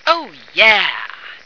flak_m/sounds/female2/int/F2ohyeah.ogg at 9e43bf8b8b72e4d1bdb10b178f911b1f5fce2398